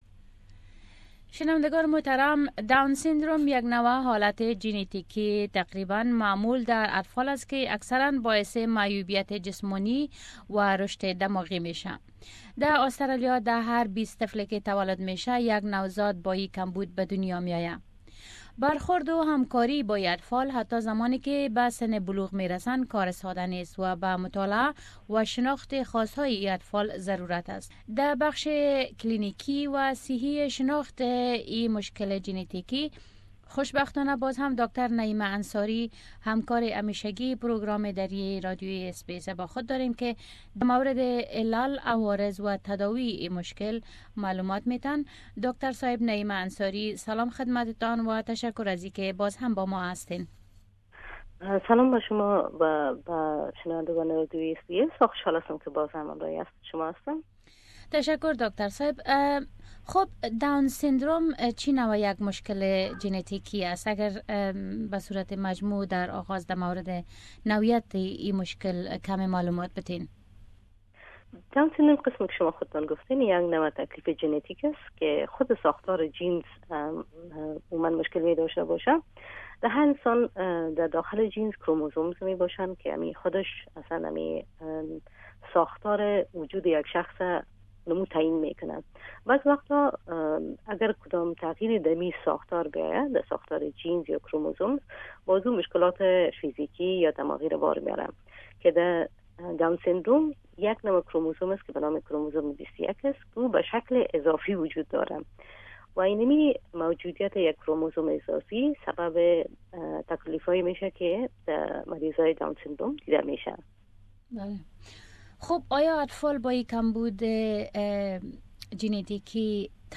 داون سندروم يك بيماري معمول جنيتيكي كي باعث معيوبيت جسماني و قوه رشد اطفال ميگردد. مصاحبه